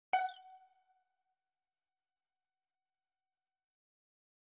دانلود آهنگ اعلان خطر 9 از افکت صوتی اشیاء
دانلود صدای اعلان خطر 9 از ساعد نیوز با لینک مستقیم و کیفیت بالا
جلوه های صوتی